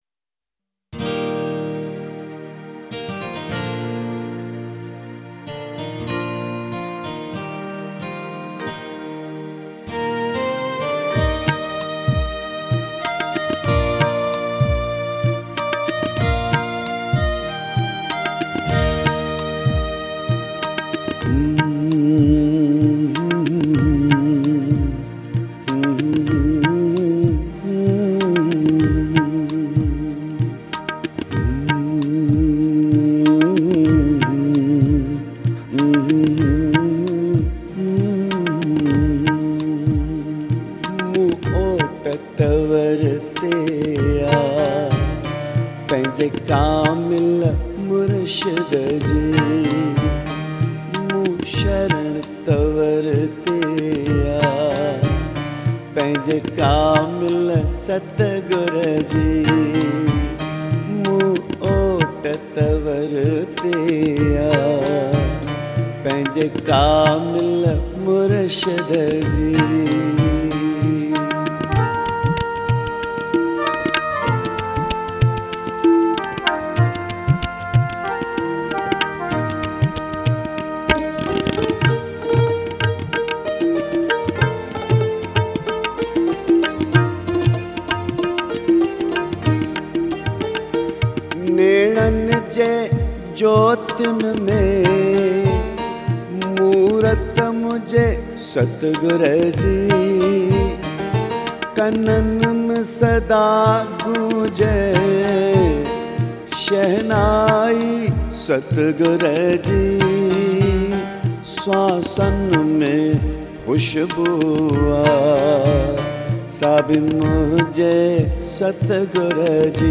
Bhajans and Dhuni songs